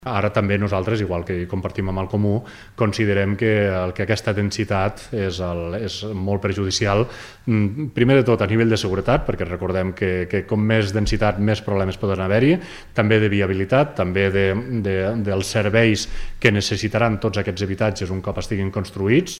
L’oposició també ha votat a favor del canvi, que fixa com a límit un habitatge per cada 400 m², fins a dos aparellats en parcel·les de 500 m² i fins a dos habitatges aïllats en superfícies de 750 m². El conseller de la minoria, Guillem Forné.